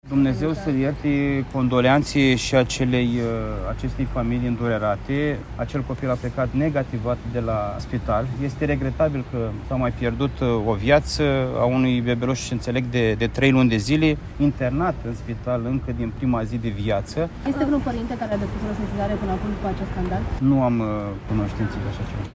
Micuțul scăpase de infecția cu bacteria Serratia, a precizat Costel Alexe, președintele Consiliului Județean Iași, în fața jurnaliștilor.